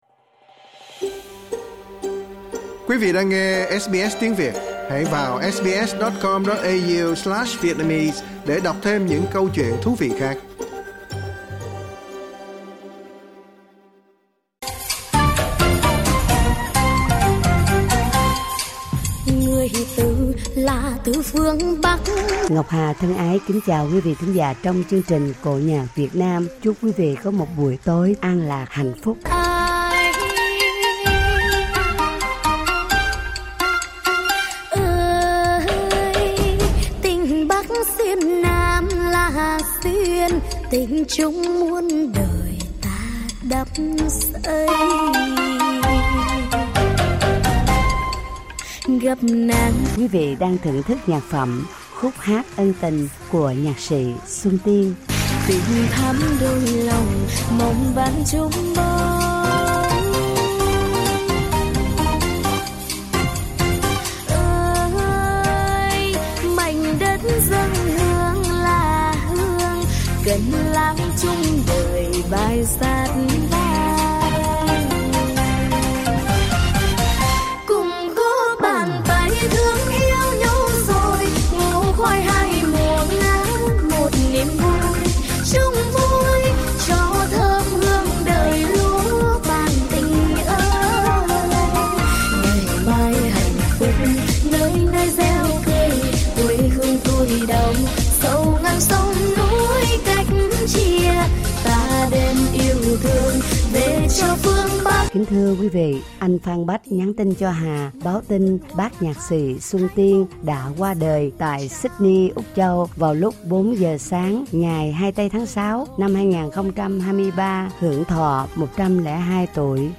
Tân cổ